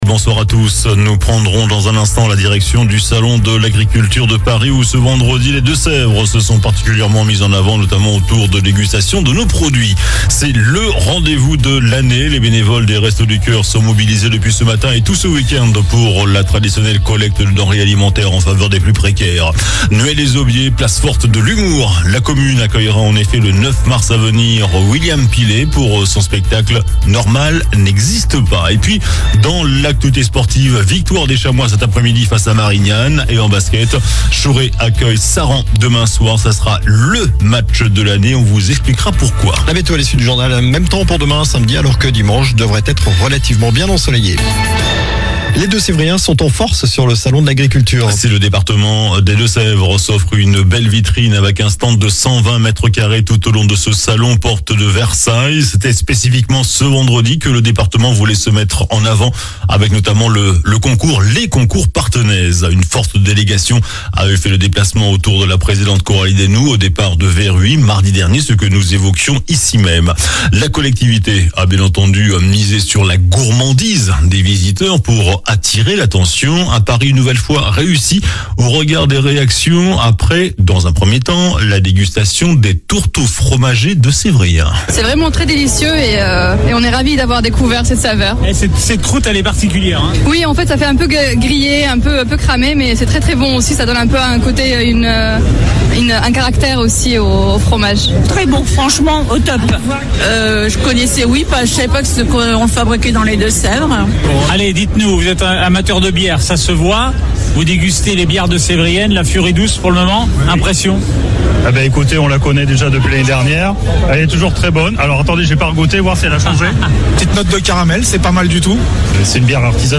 JOURNAL DU VENDREDI 01 MARS ( SOIR )